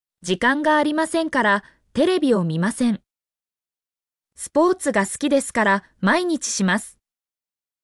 mp3-output-ttsfreedotcom-30_LZJkRFNA.mp3